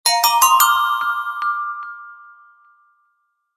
Index of /phonetones/unzipped/Sony Ericsson/Xperia-X10/notifications
tinkle.ogg